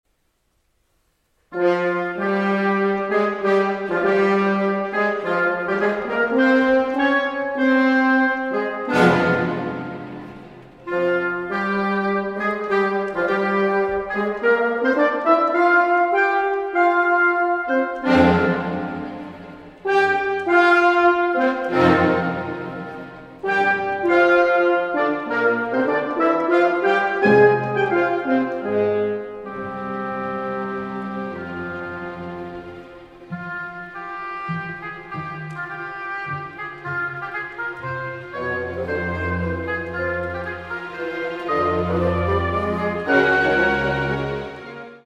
Die Harfe hält den Faden, das Horn ruft zum Höhepunkt.